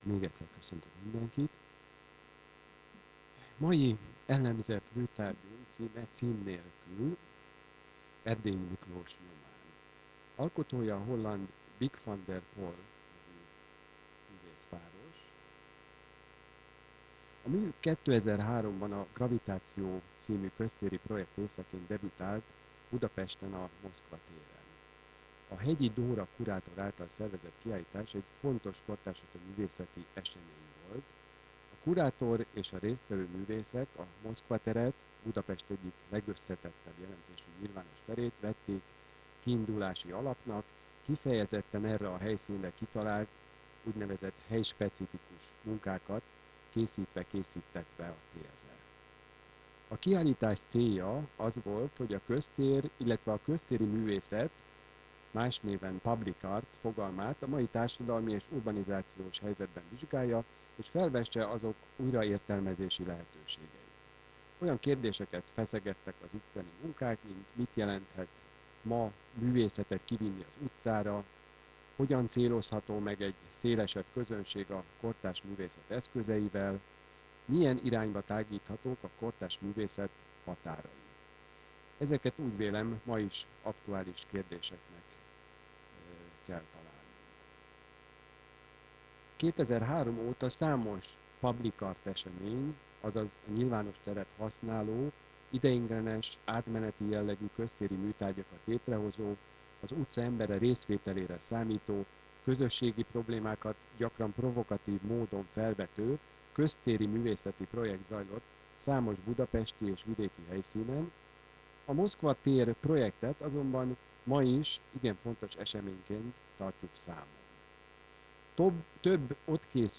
Érthetetlen-e a kortárs művészet?Műértelmezési előadások, műelemzési gyakorlatok